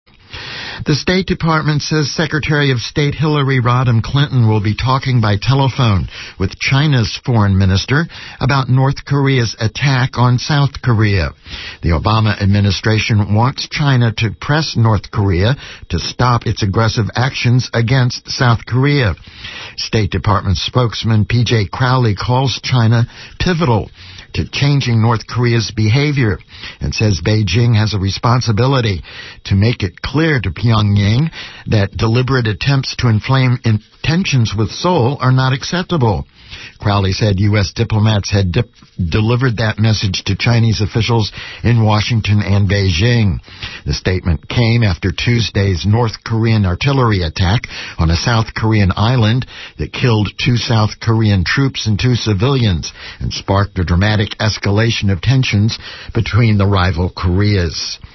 Here are the excerpts regarding Korea from the KPFA news headlines at Noon and 4 PM today.